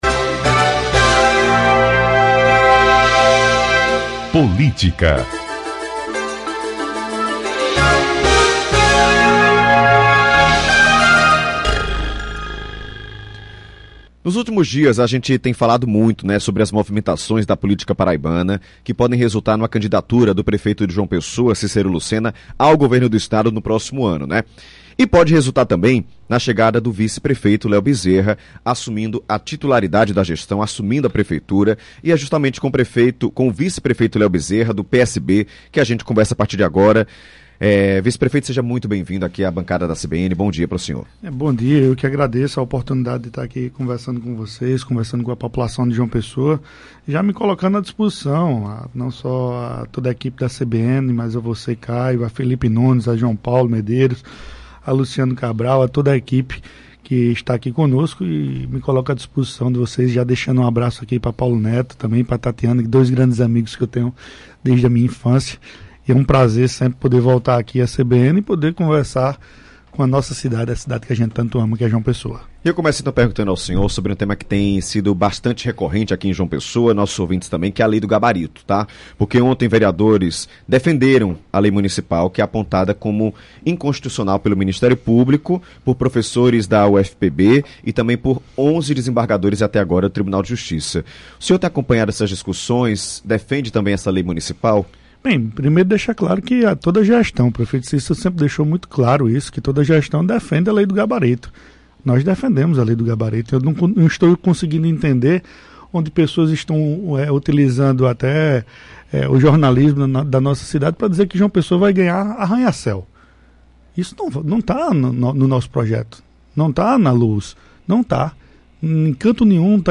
O vice-prefeito de João Pessoa, Leo Bezerra (PSB), concedeu entrevista à CBN João Pessoa nesta quarta-feira (29) e falou sobre o cenário político local, a posição do PSB e sua relação com o governador João Azevêdo e o prefeito Cícero Lucena. Durante a entrevista, o vice-prefeito destacou que não tem obrigação de votar em Lucas Ribeiro, pré-candidato ao governo estadual, e reforçou sua lealdade a João Azevêdo e Cícero Lucena.